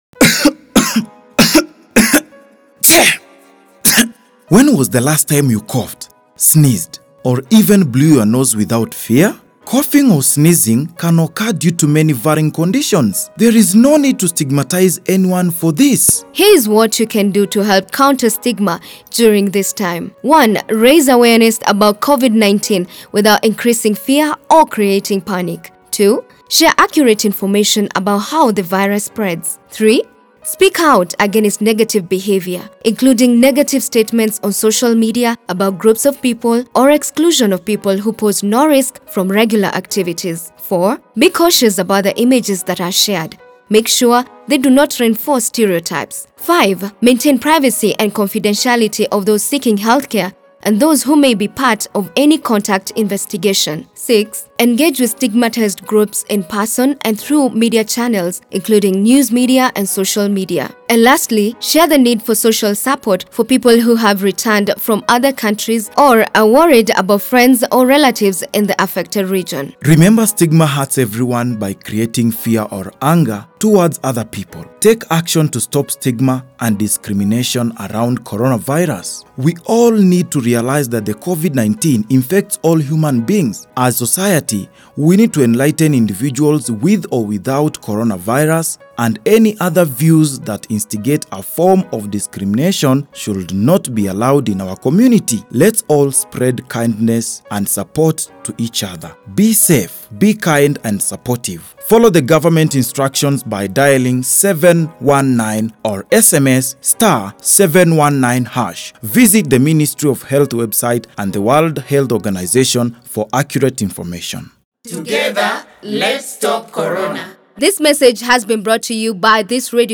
Sports for Development Informercial